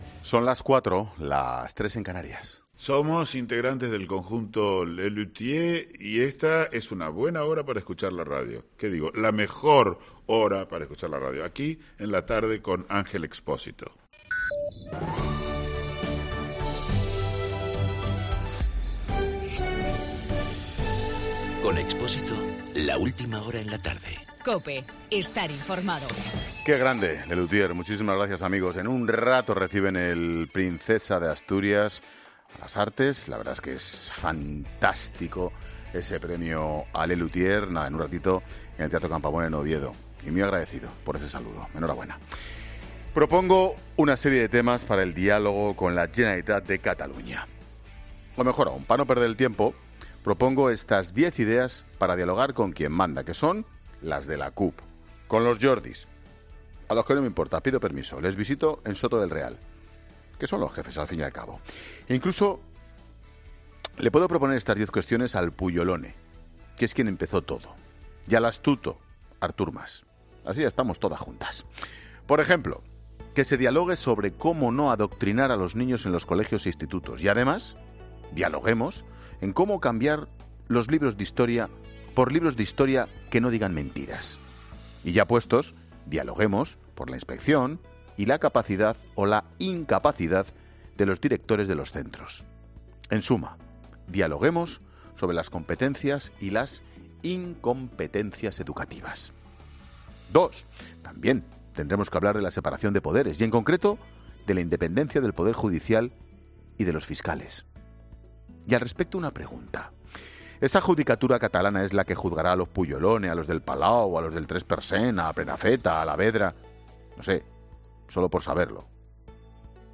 AUDIO: El comentario de Ángel Expósito.